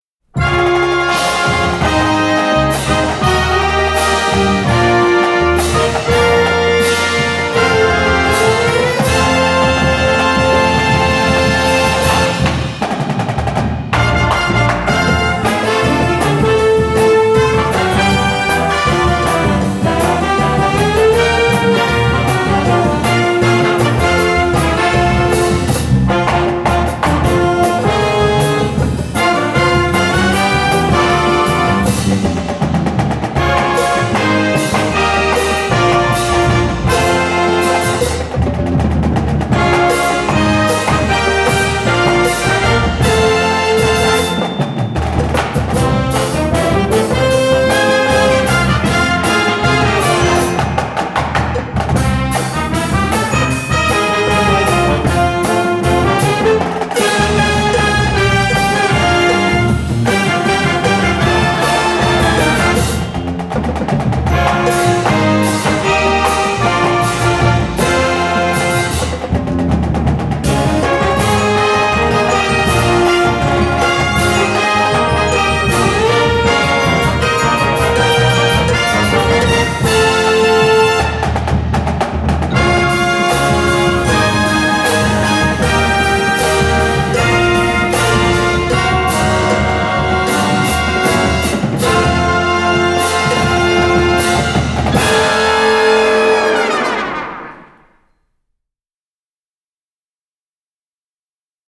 Gattung: Filmmusik für Marching Band
Besetzung: Blasorchester